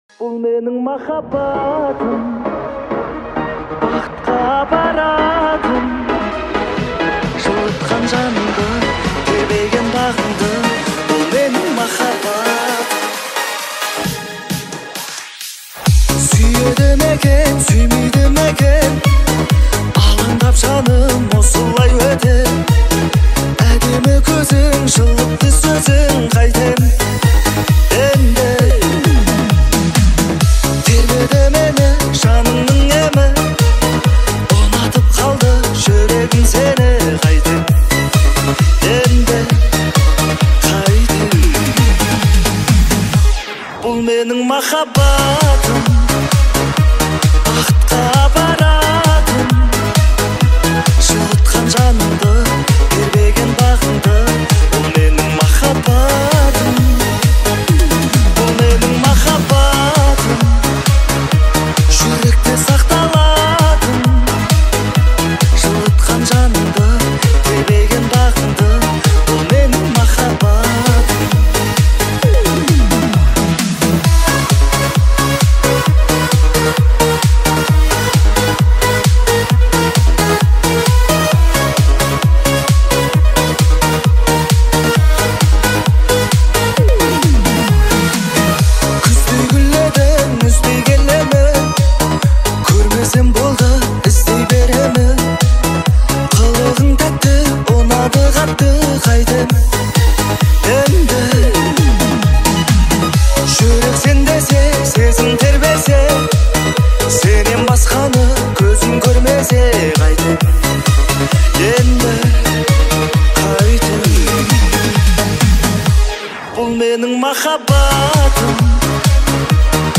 Категория: Казахские песни